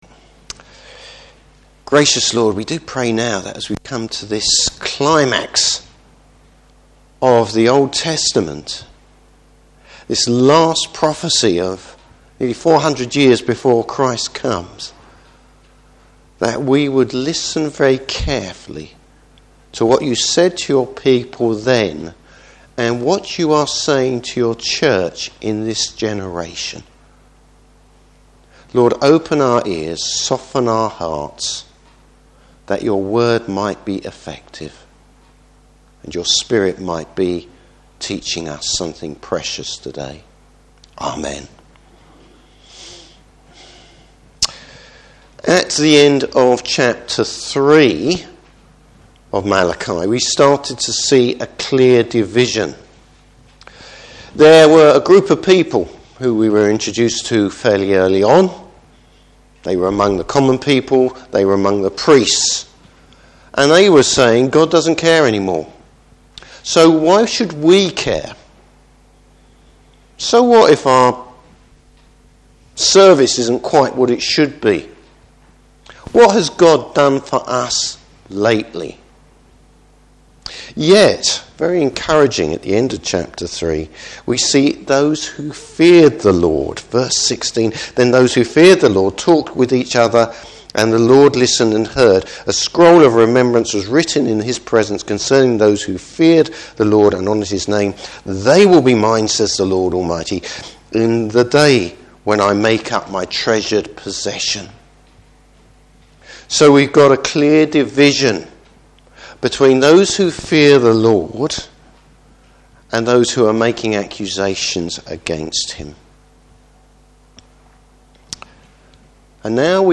Service Type: Morning Service The Day of the Lord.